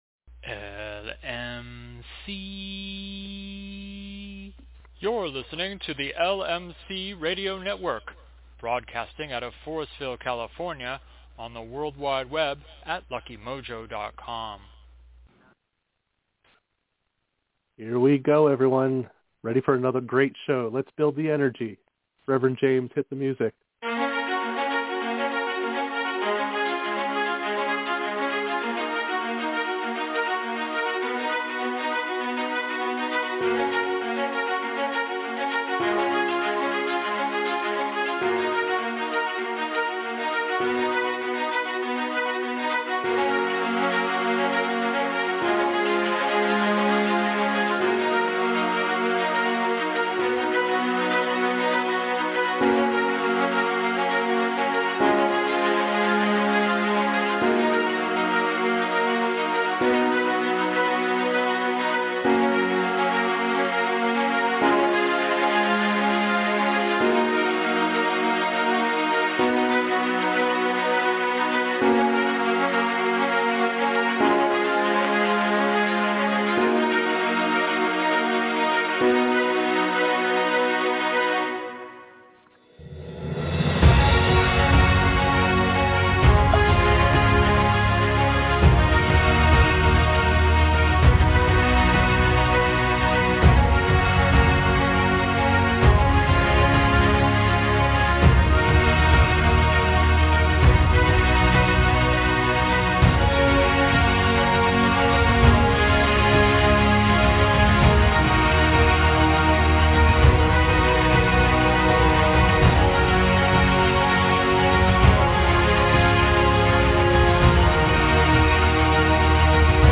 We begin this show with an interview of our guest followed by a discussion on astrological magic, it's history, and it's applications.